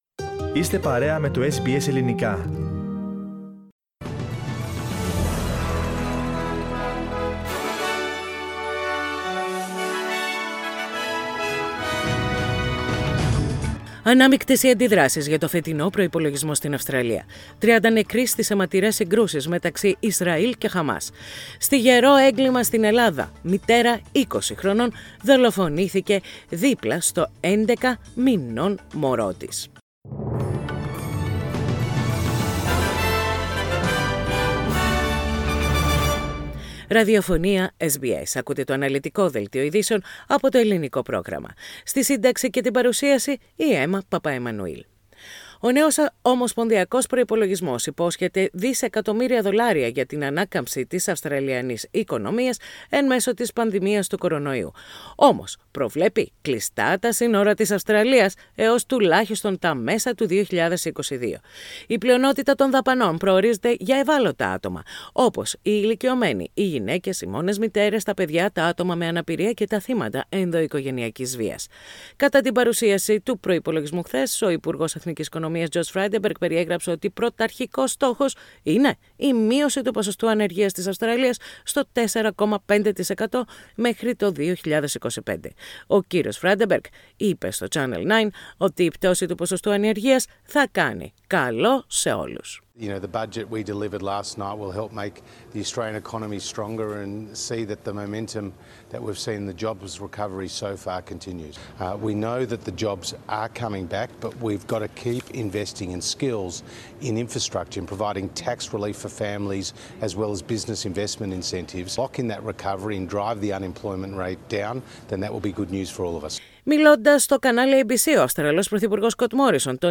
Main news of the day from SBS radio Greek program.